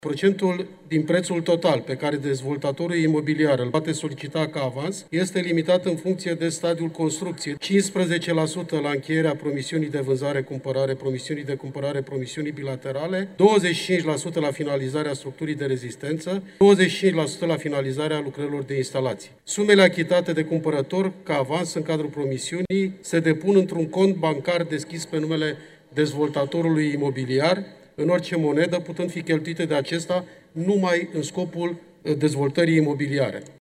Legea Nordis a fost dezbătută azi în Camera Deputaților. Este legea care limitează avansul imobiliar și prevede clar că banii nu pot fi folosiți de dezvoltatorii imobiliari decât pentru ridicarea construcției.
Florin Roman, deputat PNL: „Procentul din prețul total pe care dezvoltatorul imobiliar îl poate solicita ca avans este limitat în funcție de stadiul construcției”